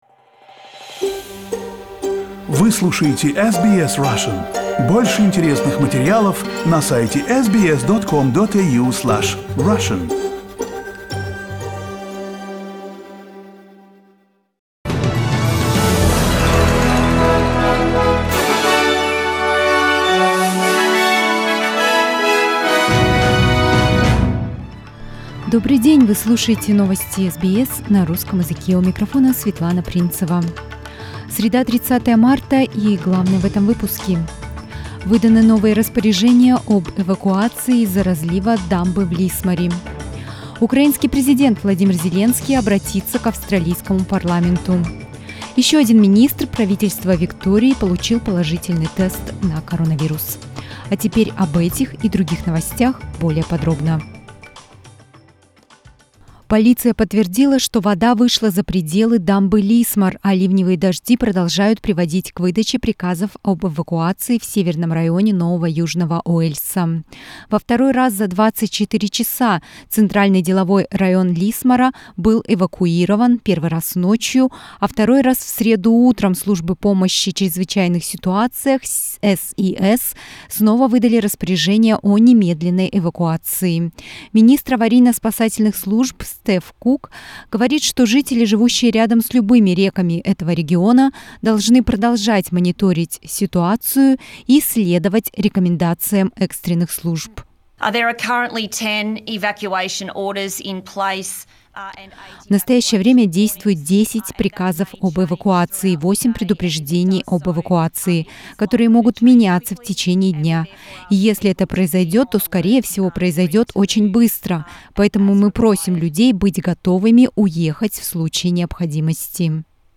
SBS News in Russian - 30.03